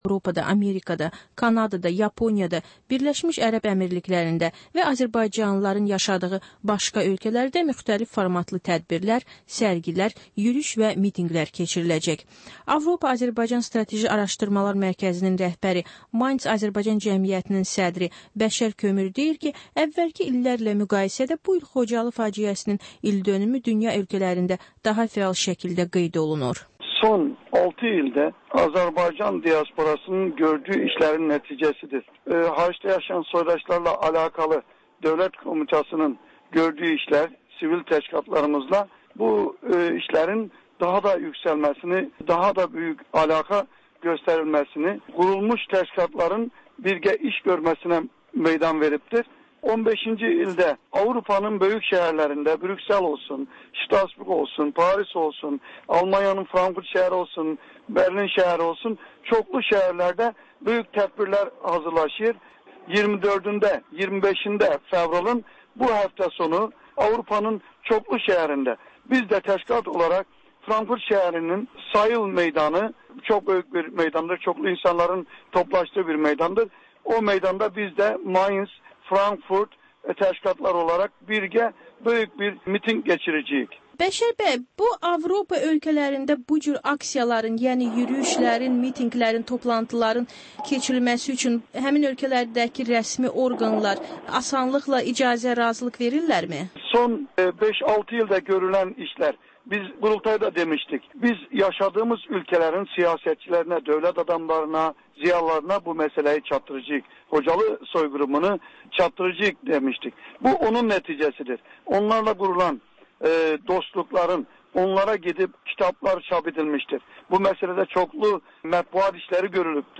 Reportaj, təhlil, müsahibə